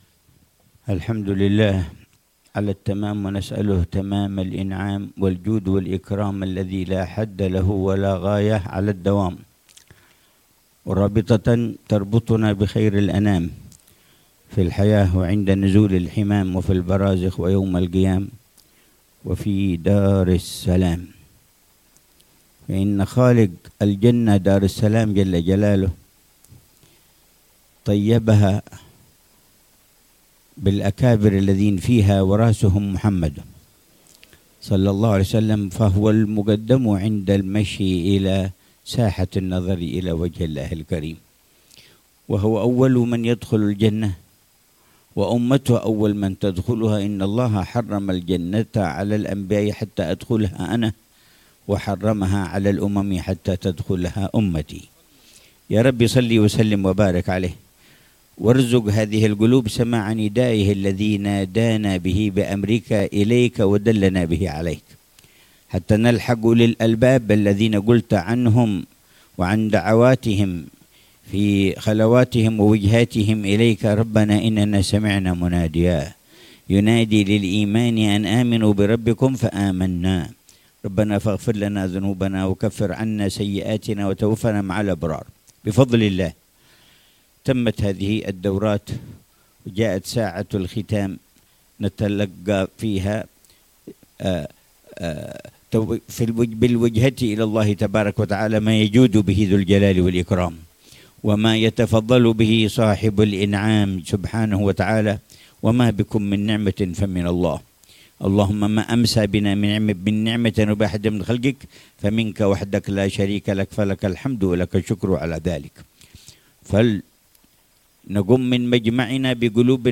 كلمة العلامة الحبيب عمر بن حفيظ في اختتام الدورة الصيفية الثانية بمعهد الرحمة، بمدينة عمّان، الأردن، عصر يوم السبت 7 ربيع الأول 1447هـ